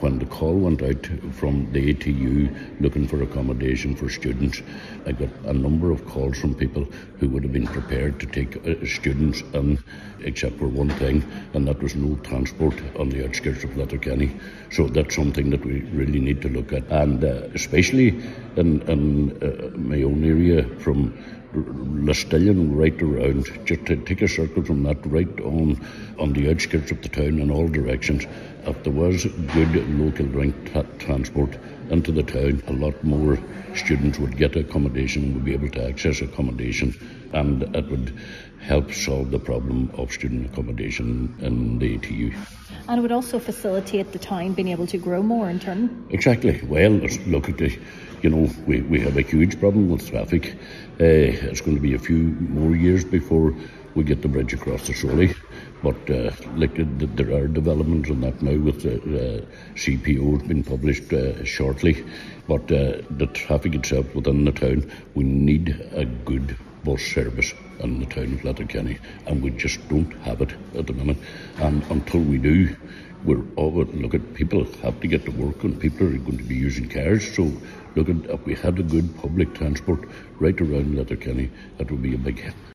Councillor Coyle says additional Local Link services would greatly enhance the accommodation offering in the town: